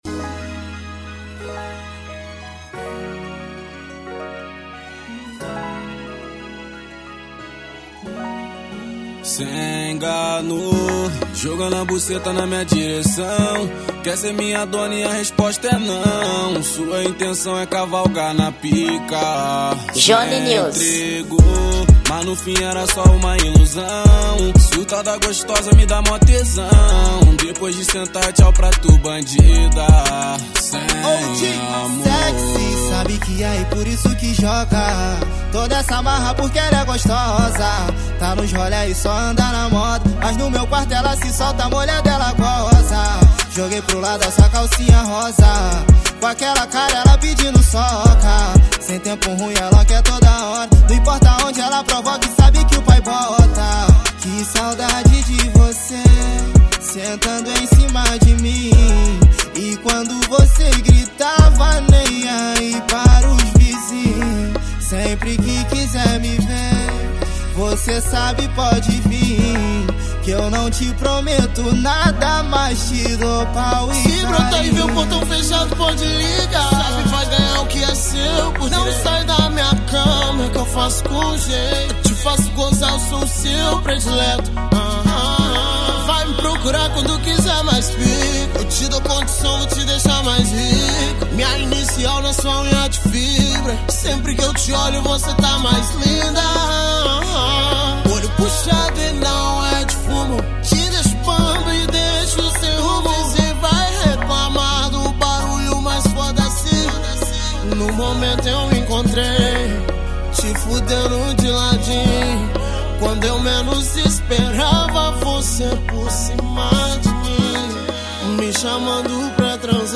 Gênero: Afro Trap